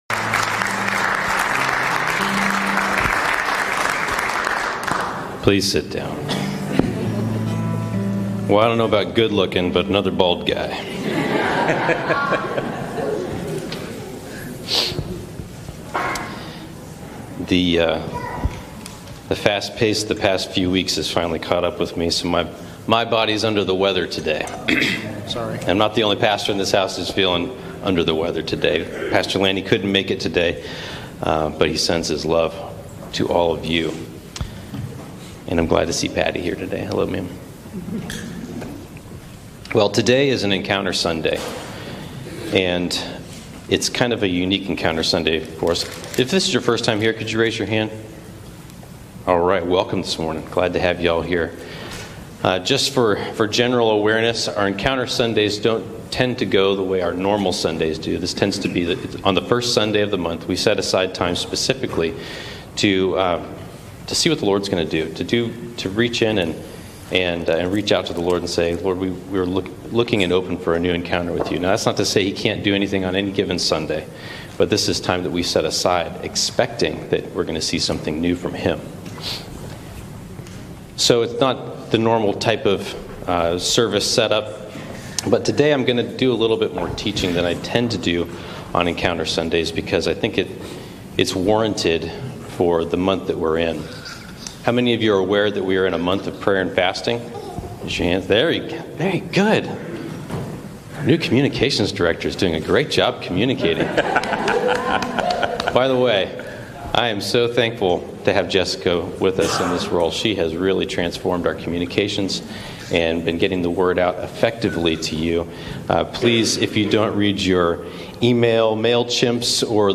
Encounter Service: His Strength in Our Weakness